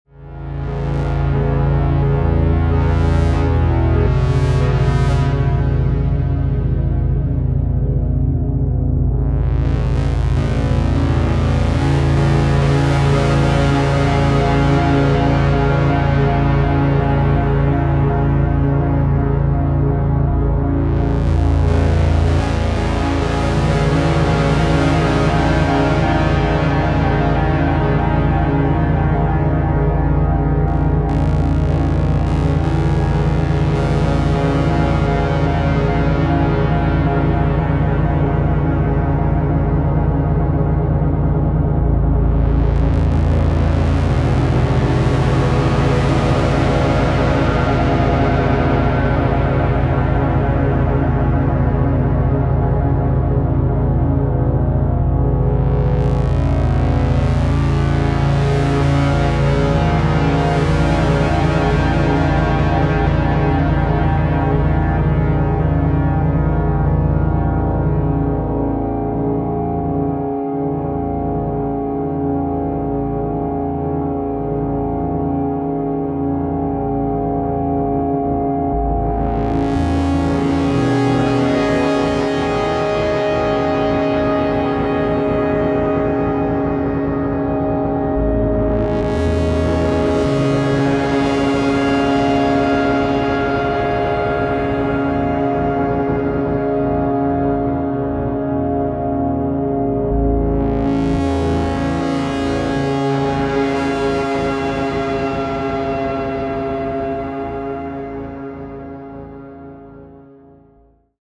metal sync dronezzz
Almost sounds like an Aztec death whistle at certain moments